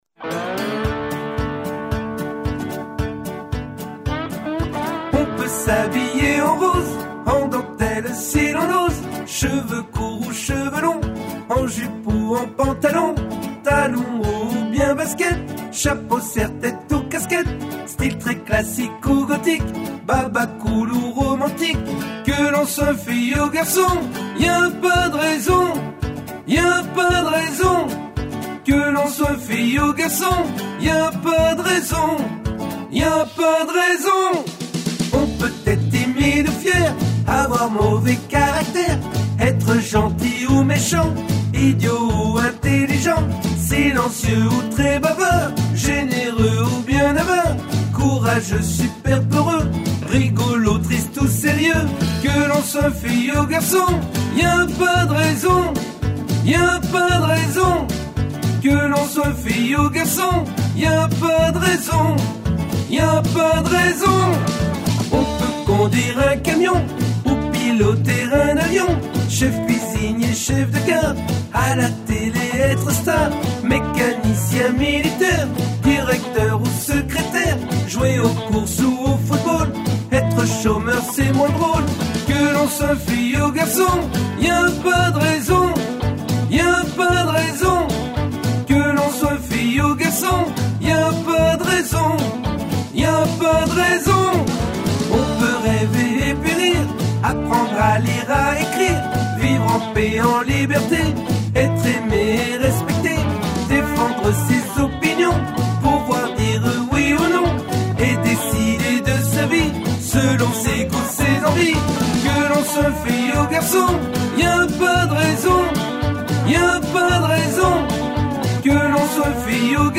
Alto